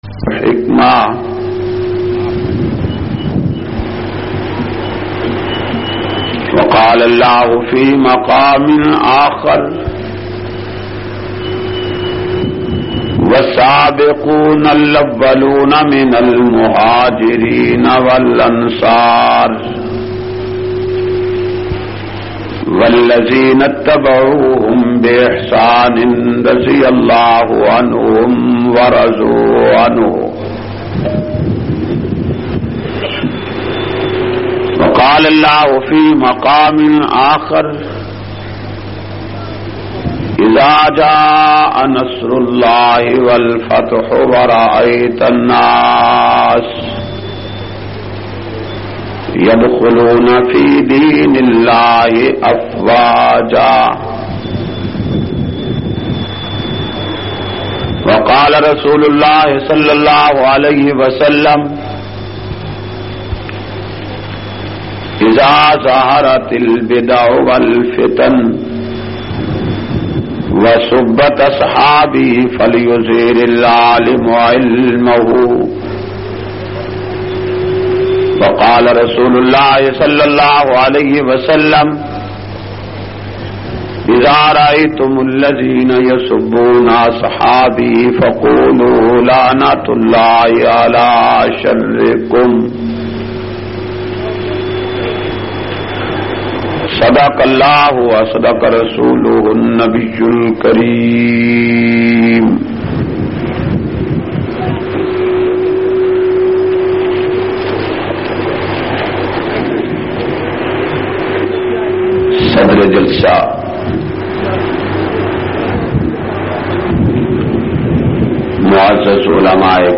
226- Shan e Sahaba Punjabi Bayan Muaza Degree Daska.mp3